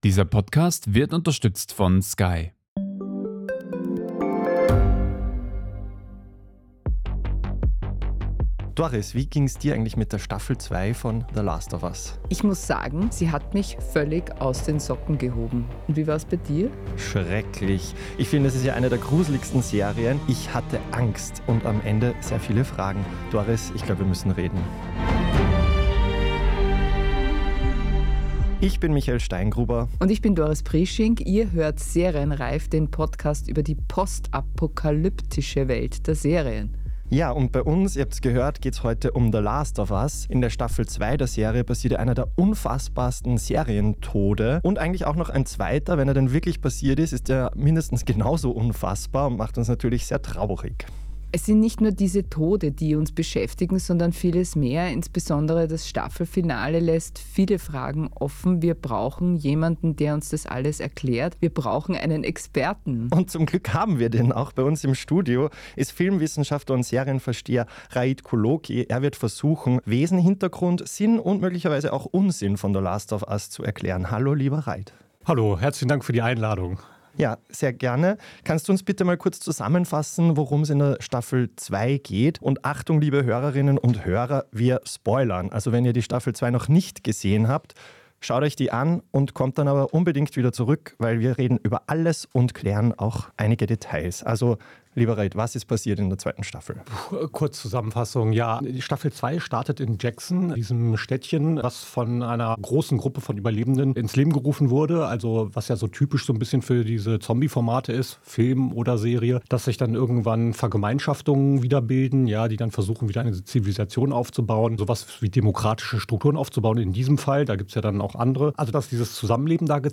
Hier wird besprochen, was die Serientäter- und täterinnen von DER STANDARD gerne sehen, geben Orientierung im Seriendschungel und diskutieren mit Experten und Expertinnen über die Hintergründe und bewegenden Themen aktueller Produktionen von Netflix, Amazon, HBO, Sky und Co. Moderation & Redaktion